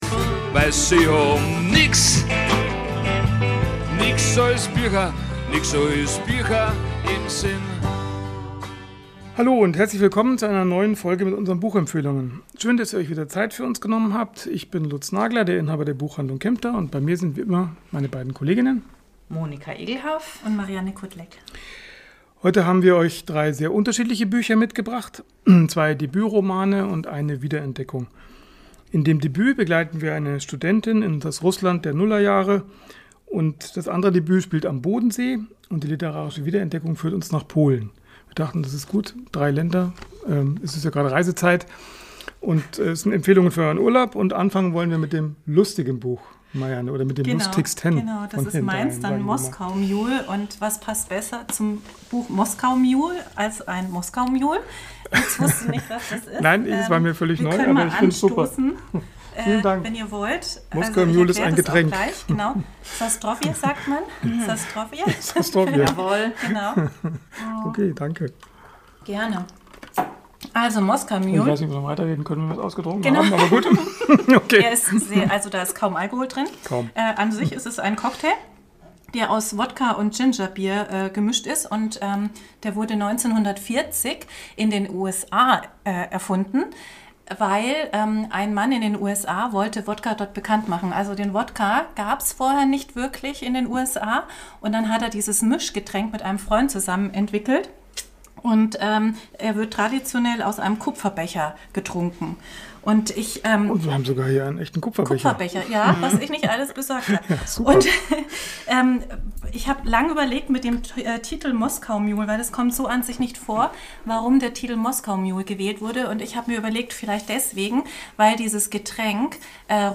Nix ois Biacha #14 ~ Nix ois Biacha - Buchempfehlungen Podcast